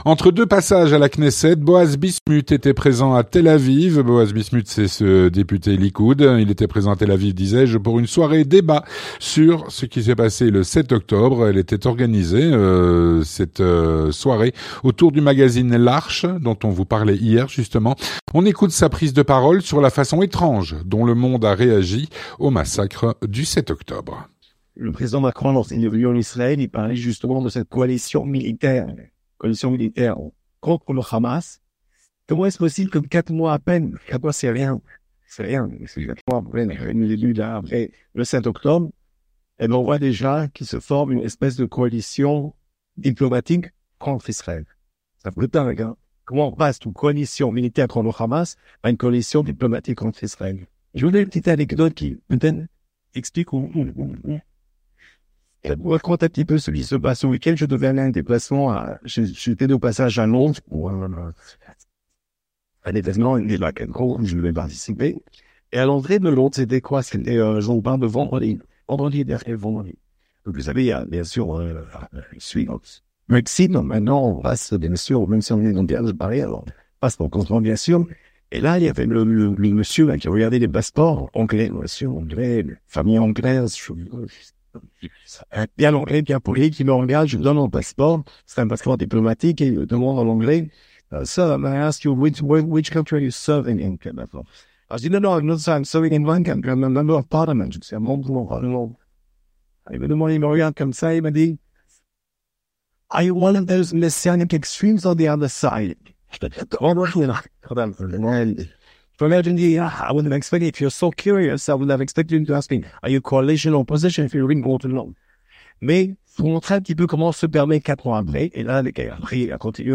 Boaz Bismuth était présent à Tel Aviv pour une soirée débat sur le 7 octobre organisée autour du magazine l’Arche.
On écoute sa prise de parole sur la façon étrange dont le monde a réagi aux massacre du 7 octobre.